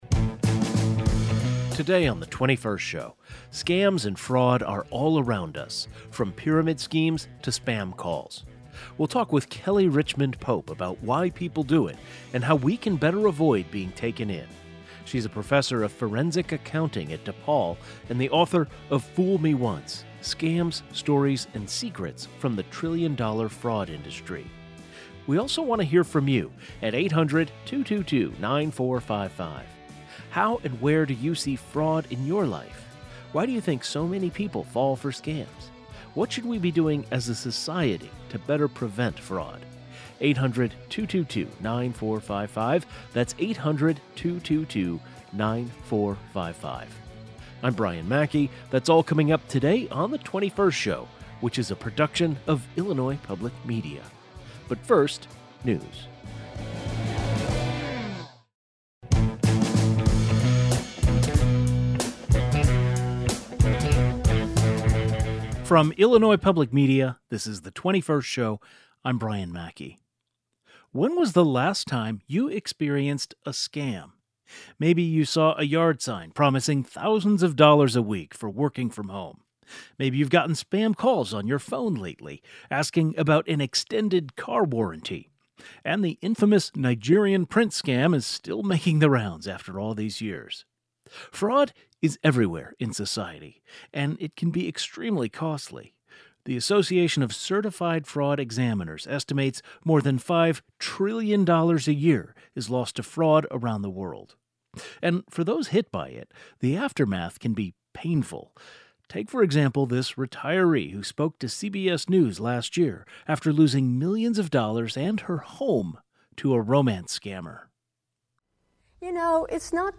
Today's show included a rebroadcast of the following "best of" segment, first aired October 16, 2025: Why do scams work? An Illinois accountant explains.